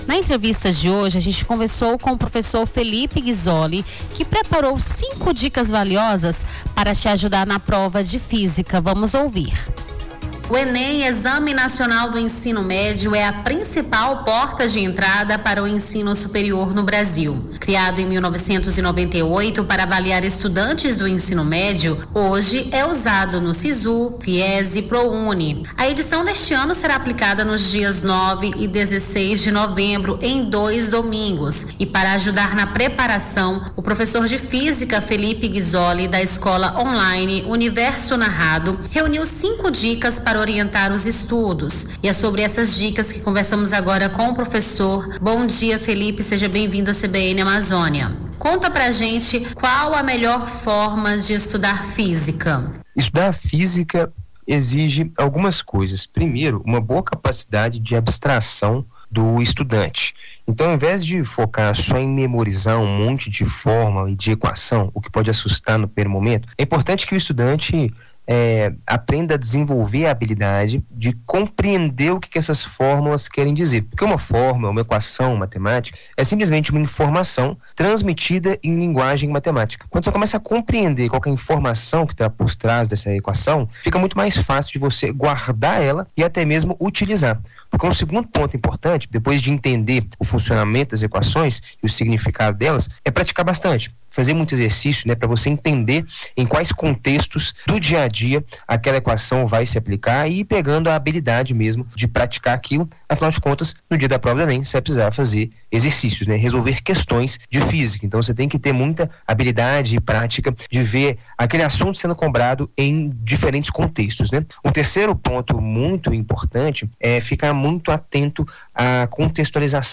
Nome do Artista - CENSURA - ENTREVISTA (DICAS DE ENEM - PROFESSOR DE FÍSICA) 12-06-25.mp3